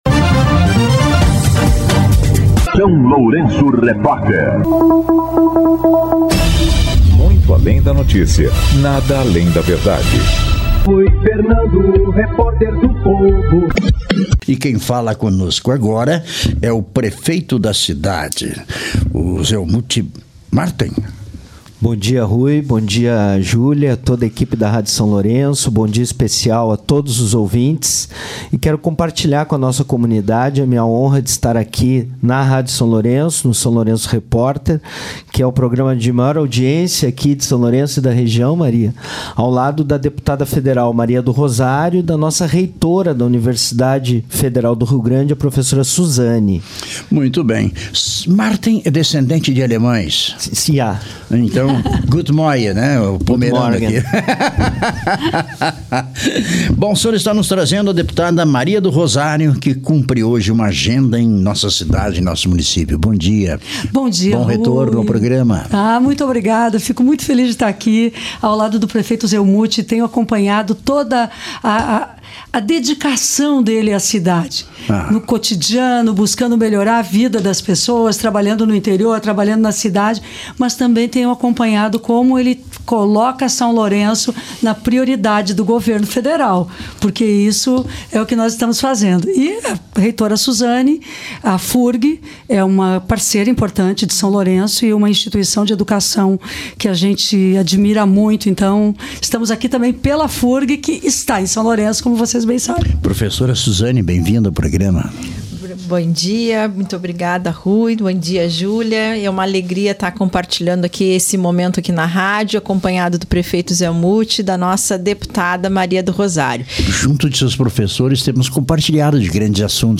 A Deputada Federal Maria do Rosário (PT) cumprindo agenda em São Lourenço Sul, acompanhada do prefeito Zelmute Marten (PT) e da Reitora da FURG Profa. Suzane Gonçalves, concedeu entrevista ao SLR RÁDIO desta sexta-feira (30) e, anunciou diversos investimentos e recursos no município.